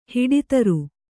♪ hiḍi taru